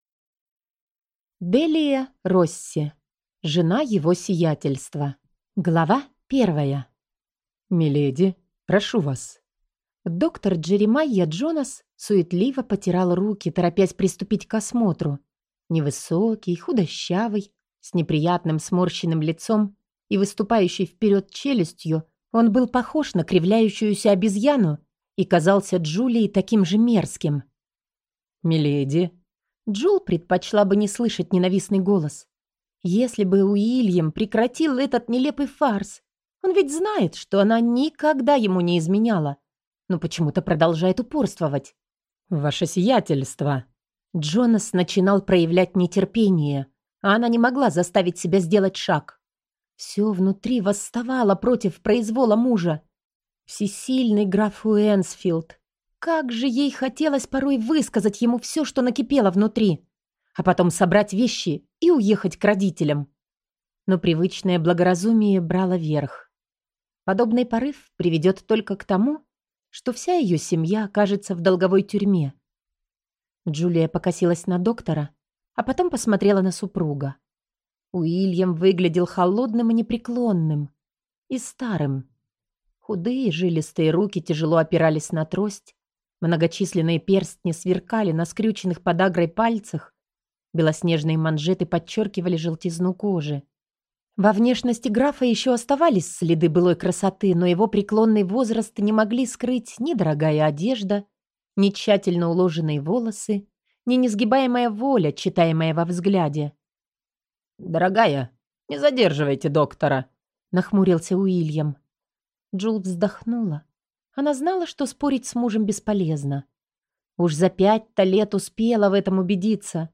Аудиокнига Жена Его сиятельства | Библиотека аудиокниг